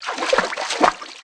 MISC Water_ Splash 01.wav